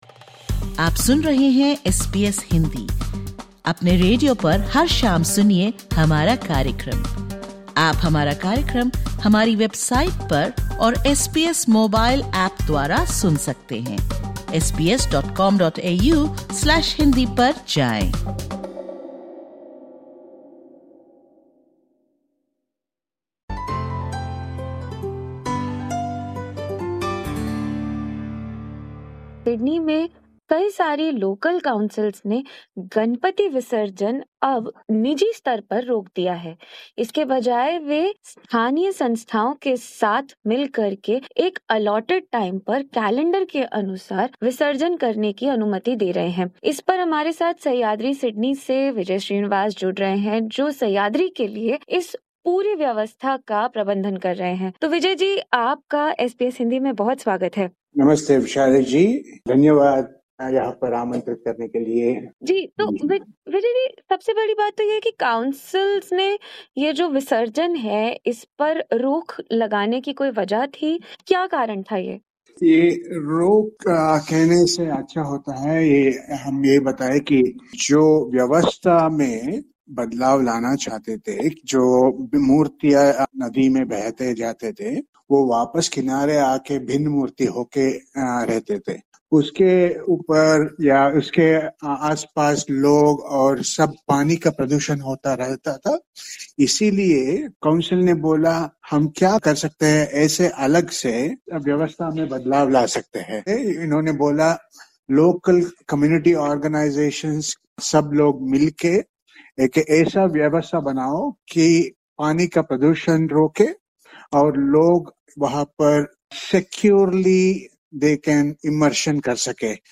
( Disclaimer: The views/opinions expressed in this interview are the personal views of the individual.